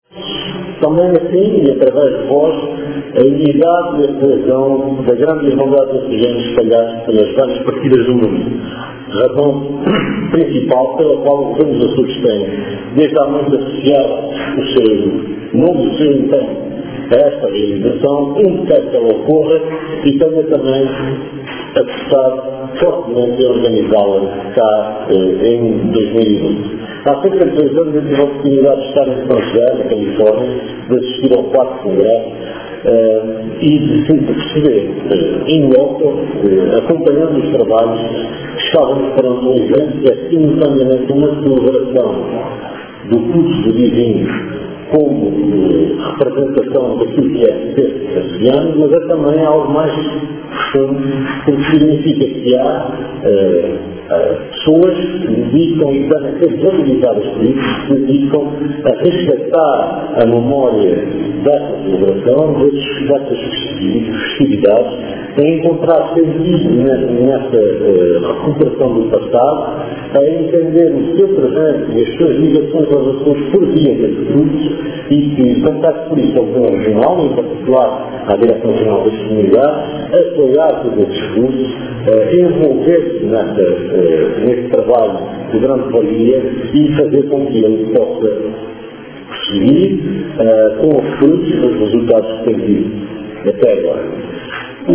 A afirmação é do Secretário Regional da Presidência quando falava na abertura do V Congresso sobre as Festas do Espírito Santo, que reúne, desde ontem e até domingo, na ilha Terceira, cerca de uma centena de estudiosos deste tema, oriundos de várias ilhas, do continente português, do Brasil, dos Estados Unidos e do Canadá.